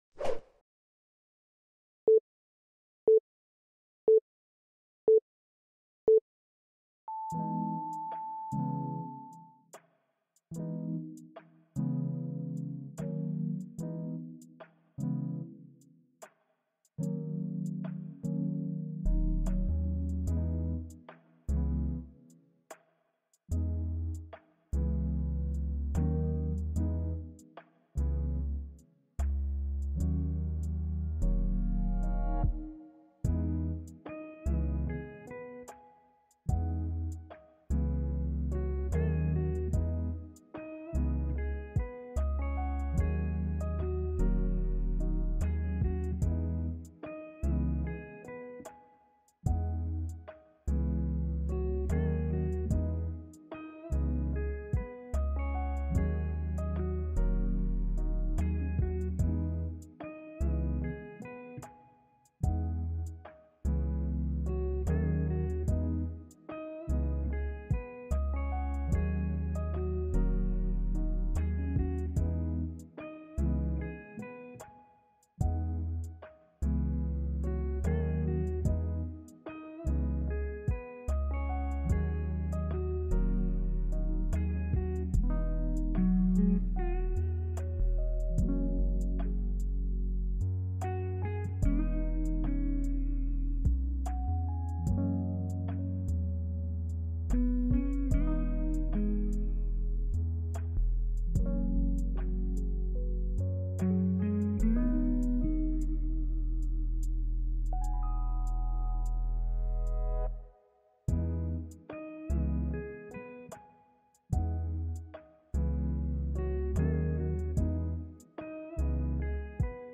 雨が静かに窓を叩くように、雨の音が思考を包み込む。リラックス音楽が、緊張をやわらげ、体の隅々まで安らぎを届ける。
目を閉じれば広がる、木々の揺れる音、風に溶ける水のせせらぎ、夜空にひっそりと響く虫の声。
勉強BGM